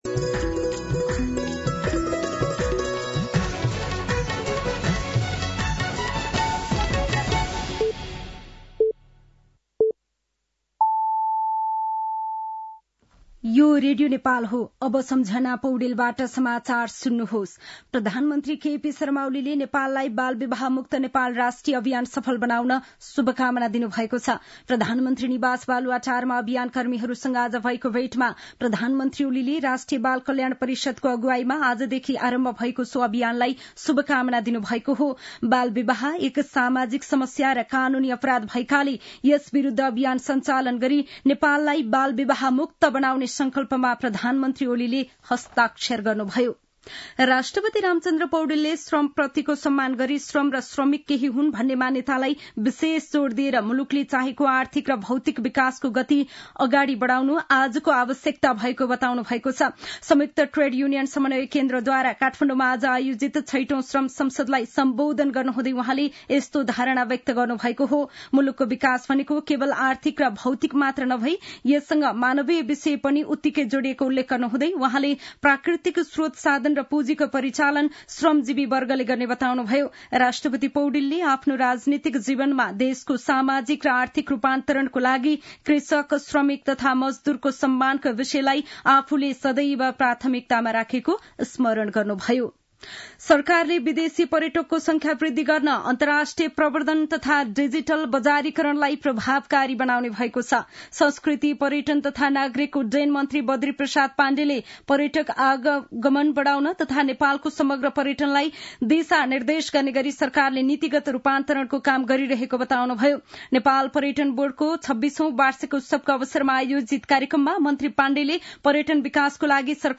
An online outlet of Nepal's national radio broadcaster
साँझ ५ बजेको नेपाली समाचार : १७ पुष , २०८१